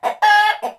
loudcluck3.wav